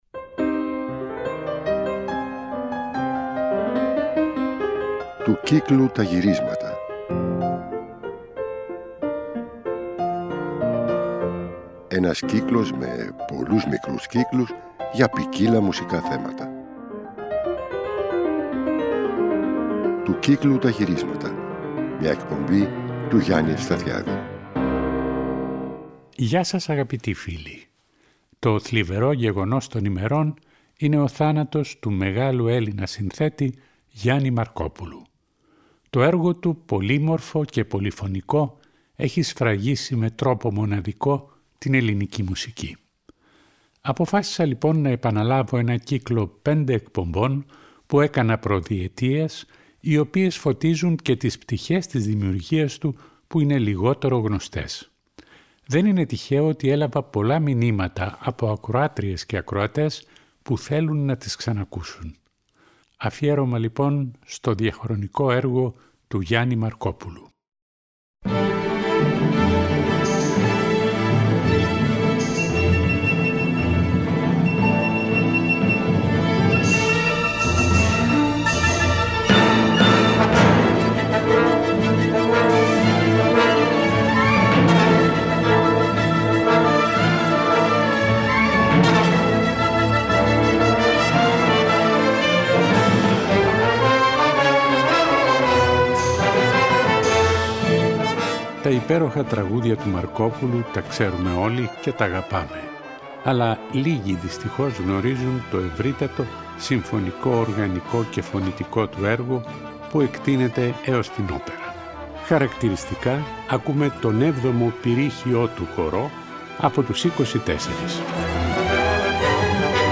Τραγούδια, κινηματογραφικές μουσικές, ραψωδίες, πυρρίχιοι χοροί, κοντσέρτα, μουσική δωματίου, ορατόρια, όπερες ακόμα και ανέκδοτα έργα του.